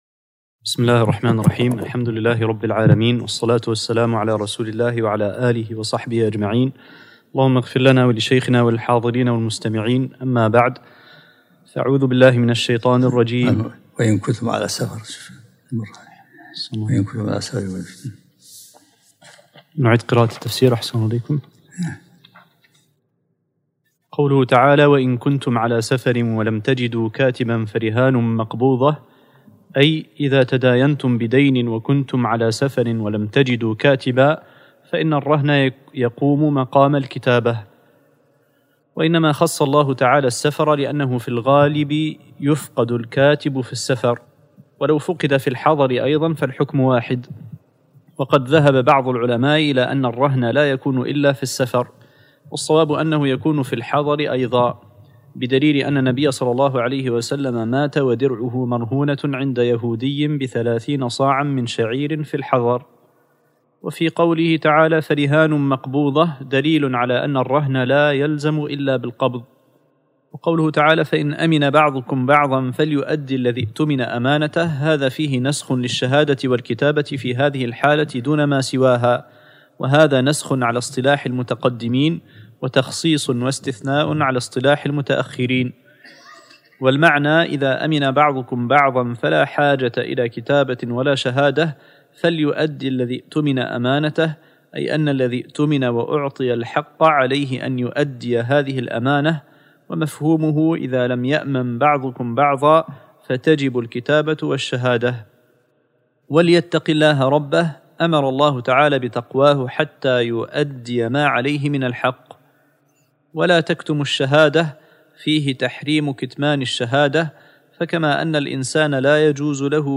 الدرس الخامس والعشرون من سورة البقرة